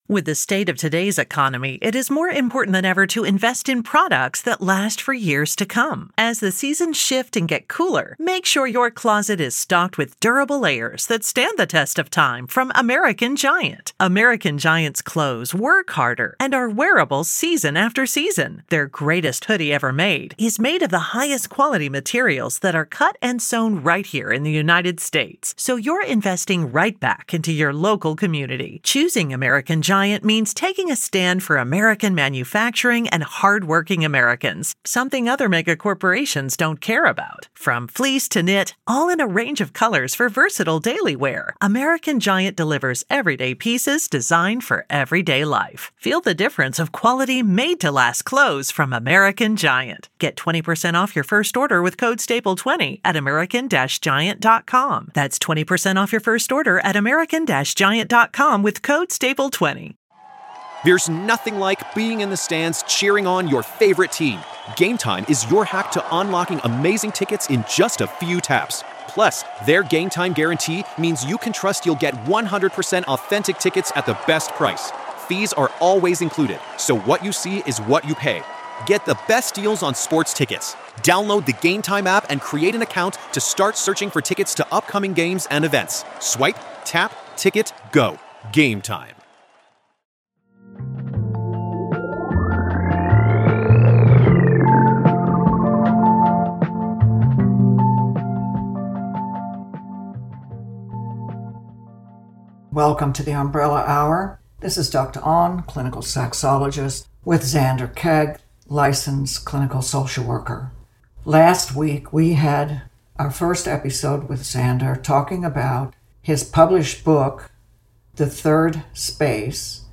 features insightful, provocative, and inspiring interviews that examine the lives of LGBT people from diverse vantage points.